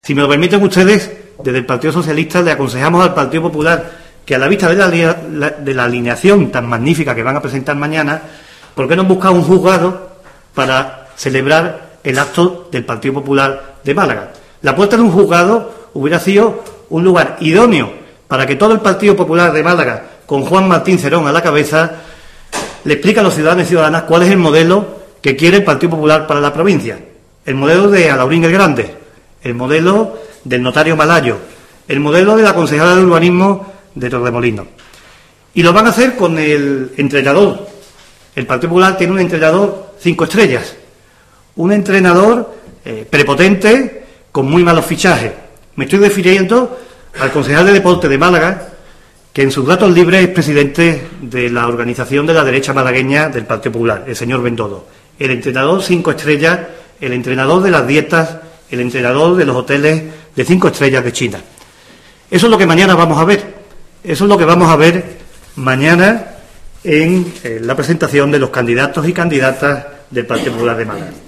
El secretario de Comunicación del PSOE andaluz, Francisco Conejo, ha asegurado hoy en rueda de prensa que "los 101 candidatos del PP en la provincia de Málaga son la derecha autóctona de cada uno de sus pueblos".